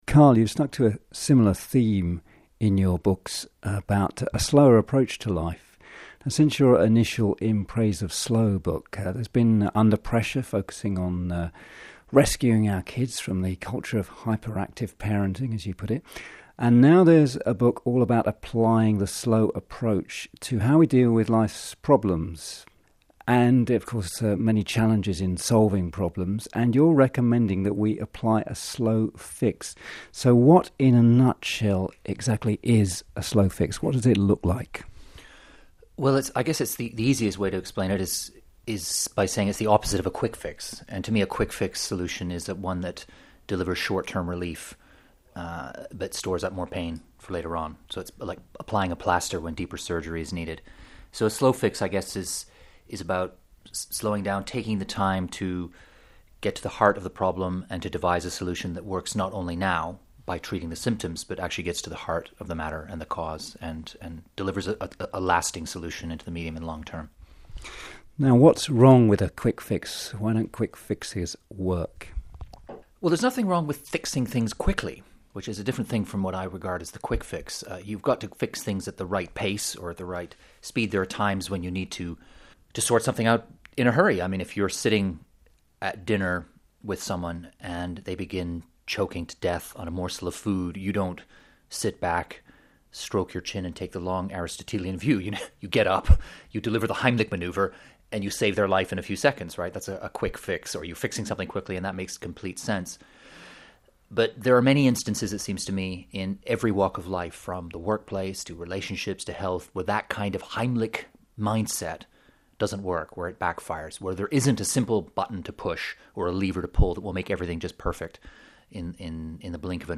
An interview I gave on The Slow Fix to the Planetary Voices website.